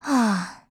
贡献 ） 协议：Copyright，其他分类： 分类:SCAR-H 、 分类:语音 您不可以覆盖此文件。
SCARH_LOWMOOD_JP.wav